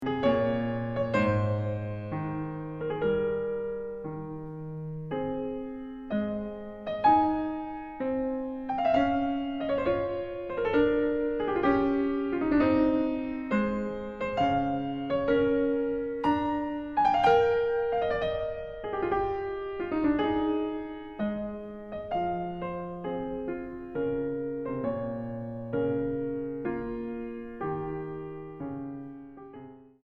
Tempo Giusto 1:44